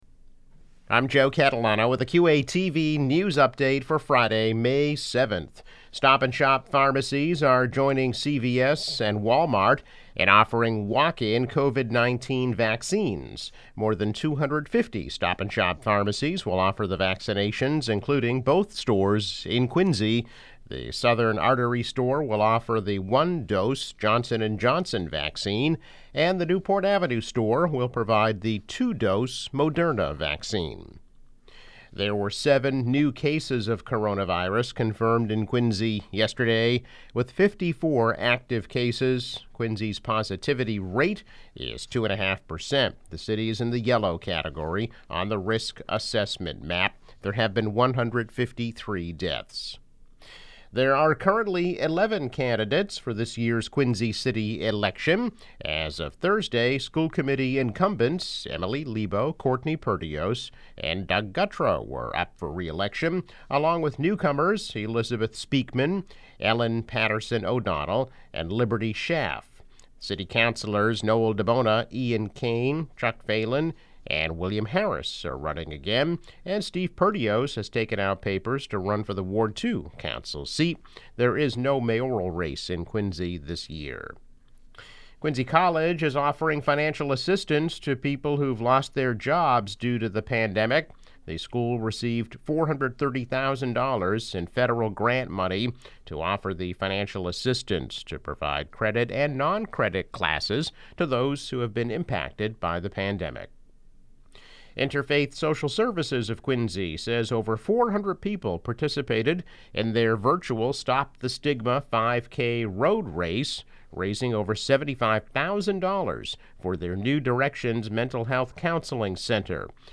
News Update - May 7, 2021